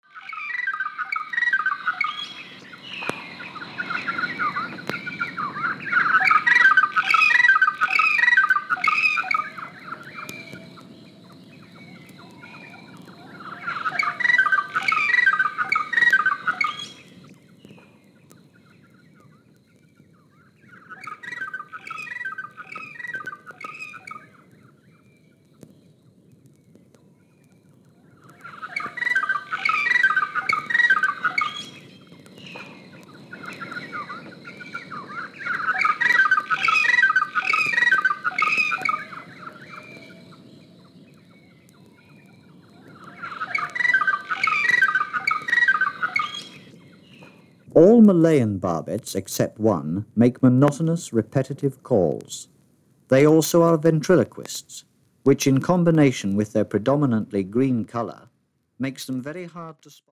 マレーシアのジャングルでフィールド録音されたレコードです。
何千年も前から変わらない鳥たちの鳴き声を淡々と説明しながら記録した作品です。